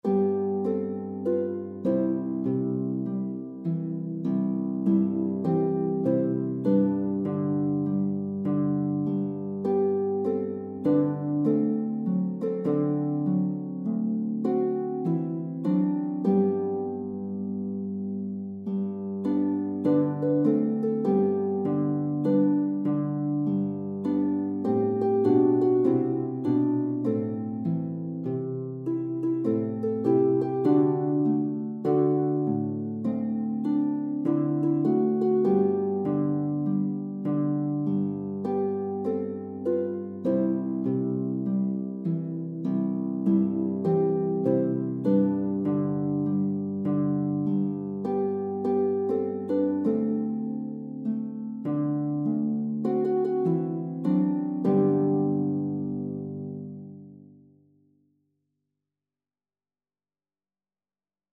Free Sheet music for Harp
4/4 (View more 4/4 Music)
G major (Sounding Pitch) (View more G major Music for Harp )
Moderato, with a swing
Harp  (View more Easy Harp Music)
Traditional (View more Traditional Harp Music)
world (View more world Harp Music)